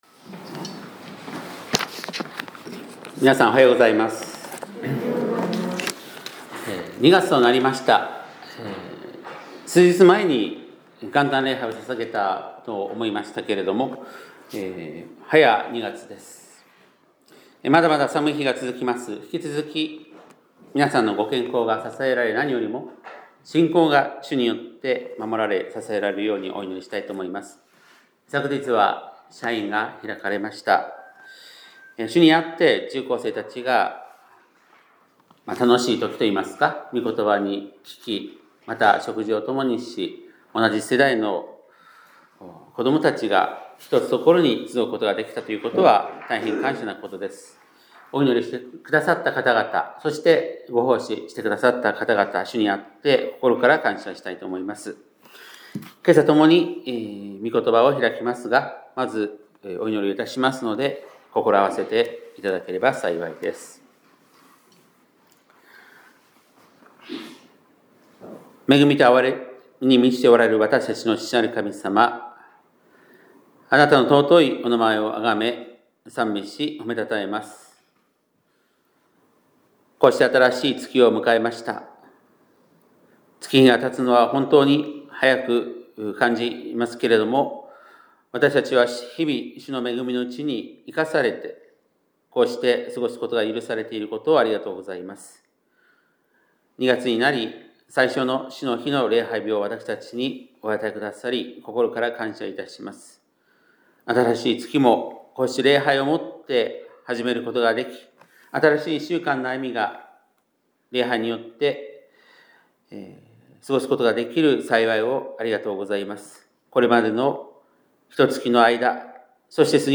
2026年2月1日（日）礼拝メッセージ - 香川県高松市のキリスト教会
2026年2月1日（日）礼拝メッセージ